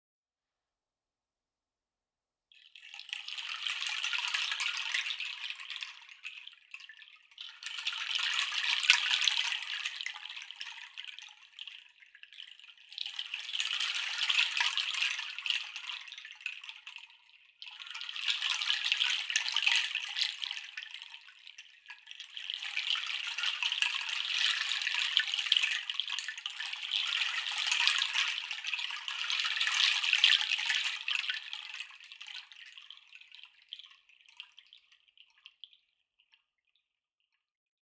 Meinl Sonic Energy Mixed Seed Chimes - Kenari / Bendo / Pangi (SKBPCH)